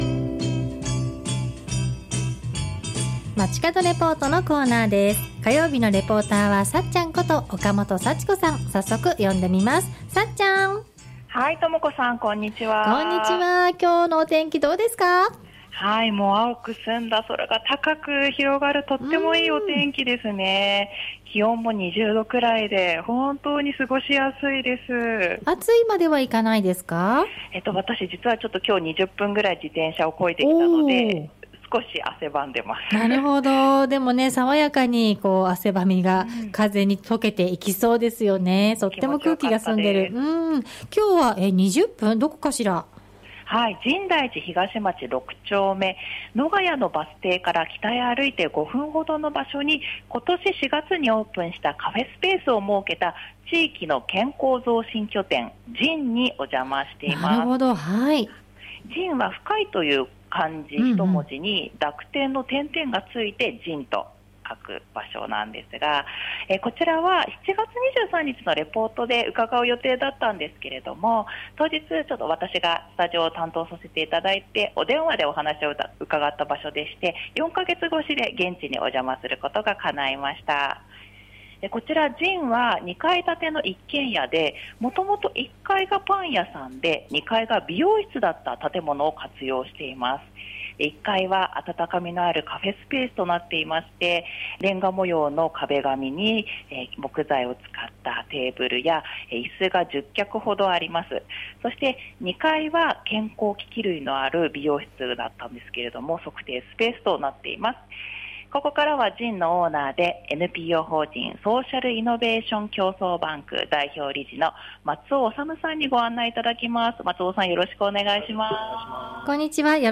サイクリングがてら、たづくりから自転車を20分走らせて 今日の中継は深大寺東町6丁目に今年4月にオープンしたカフェスぺ―スを設けた地域の健康増進拠点「深”(JIN)」からお届けしました。